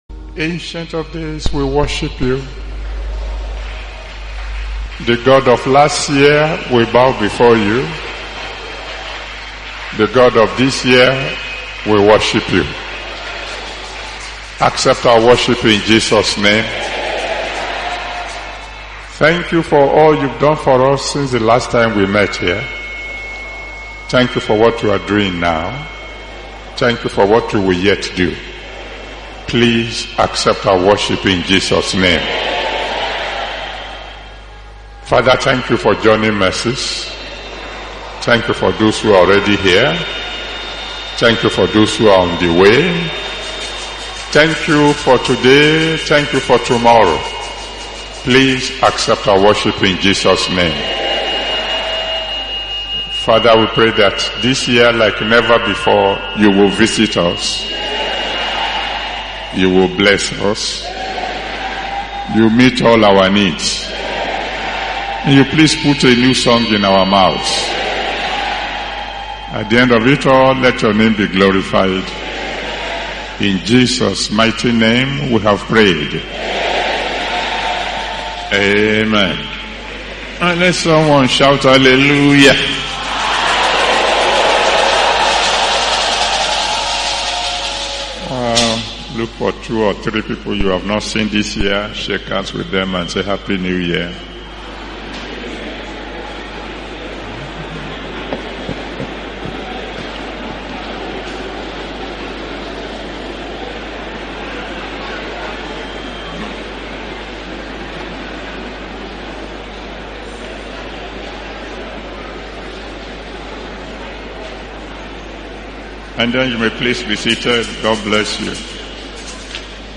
Listen to this sermon now to discover these equations!